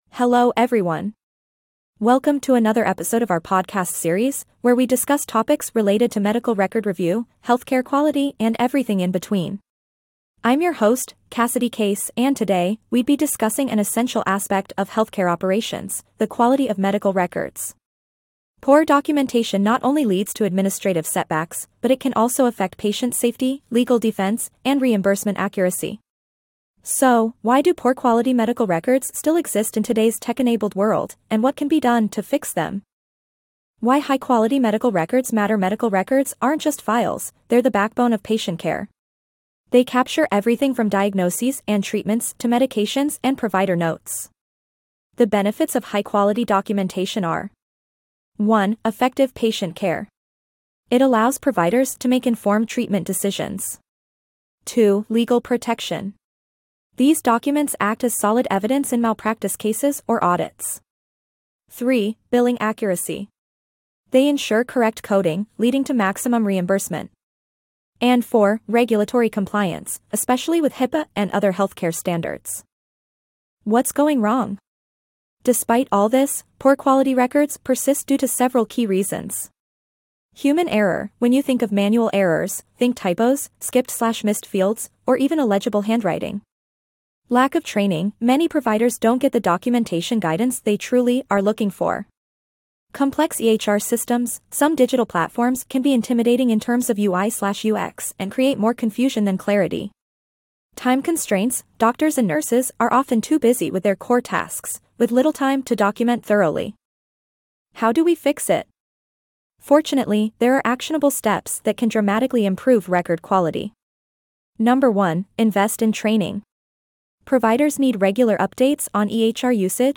Disclaimer: This podcast features human-written content, narrated using AI-generated voice.